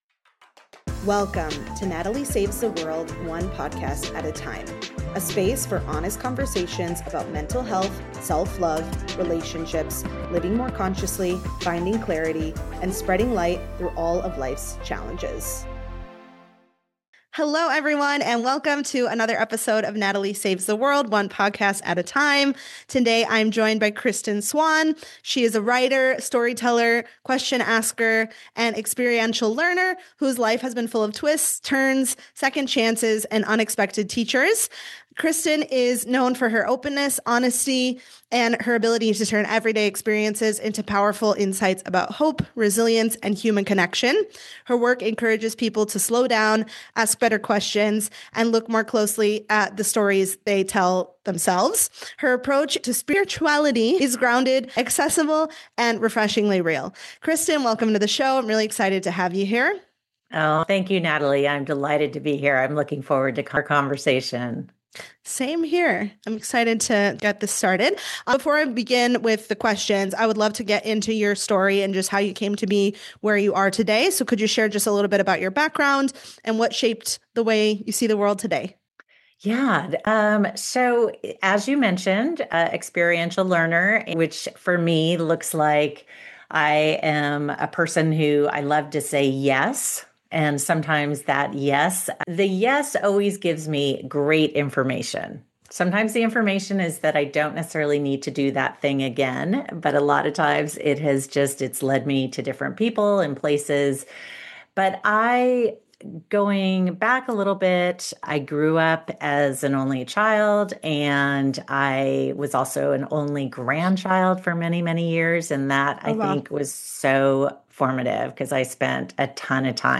a grounded conversation about spirituality that actually feels real